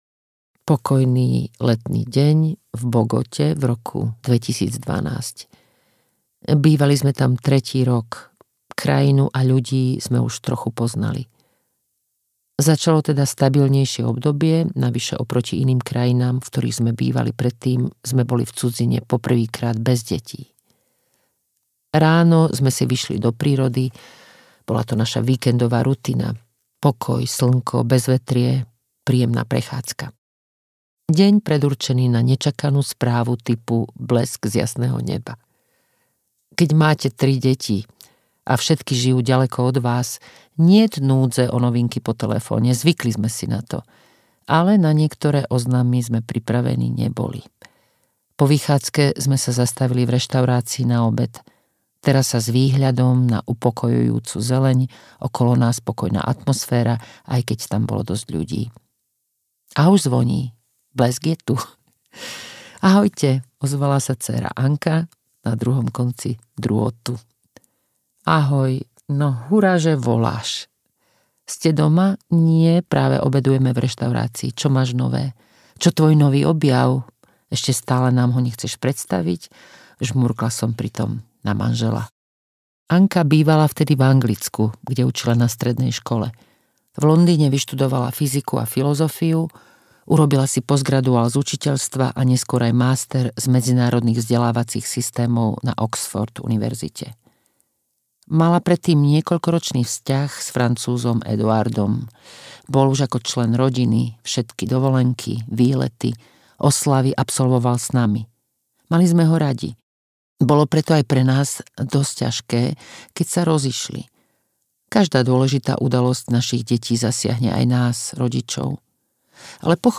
Niečo ti chcem povedať audiokniha
Ukázka z knihy